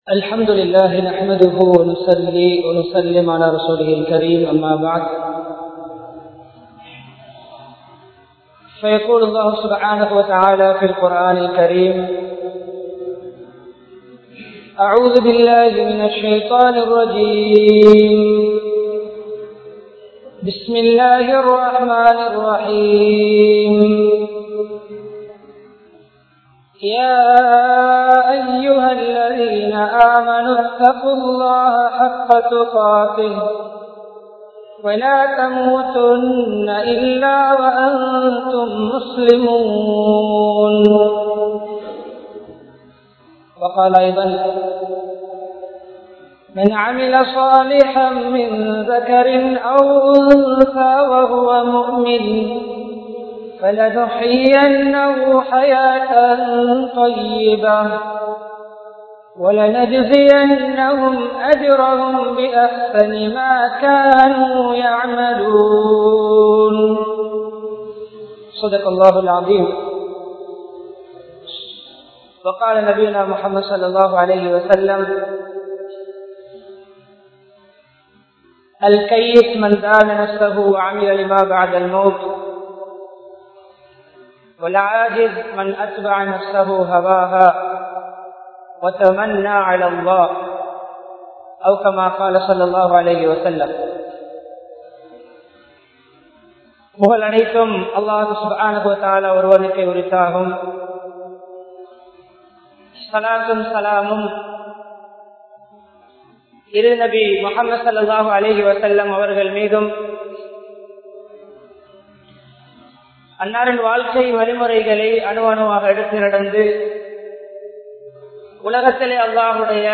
Namathu Ilakku Marumai (நமது இலக்கு மறுமை) | Audio Bayans | All Ceylon Muslim Youth Community | Addalaichenai
Mannar, Uppukkulam, Al Azhar Jumua Masjidh